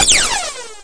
Shot1.ogg